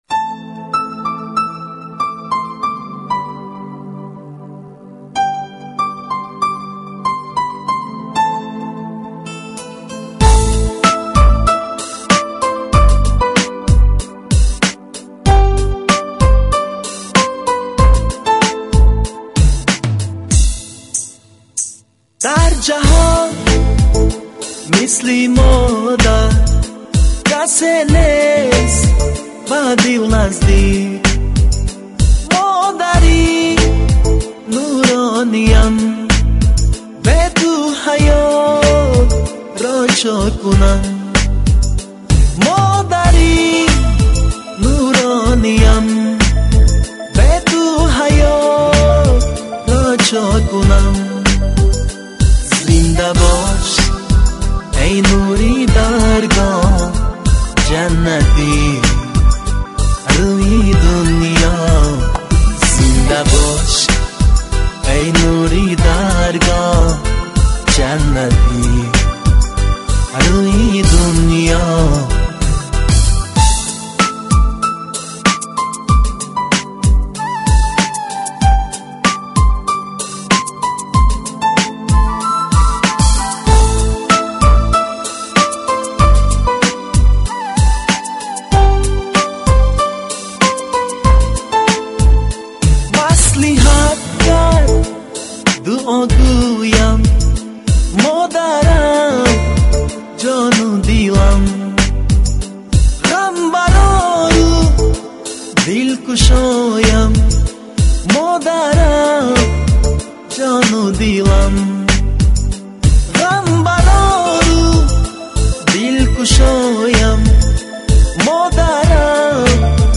Категория: Эстрада